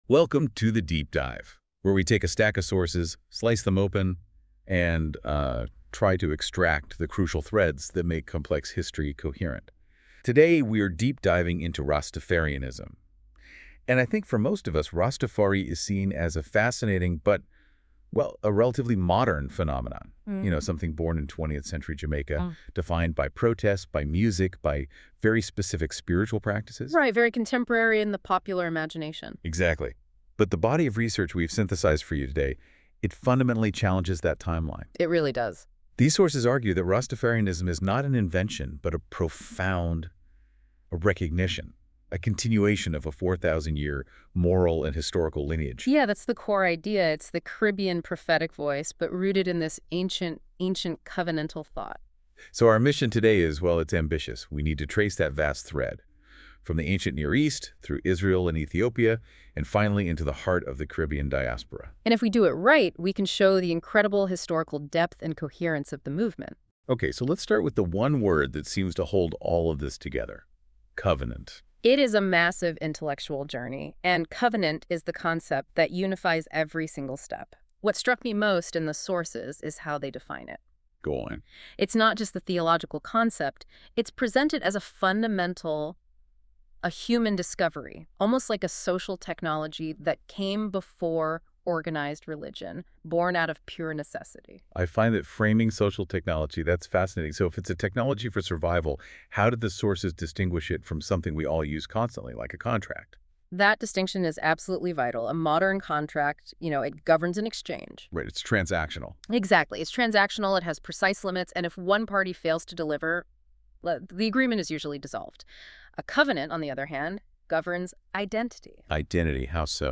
Deep Dive Audio Overview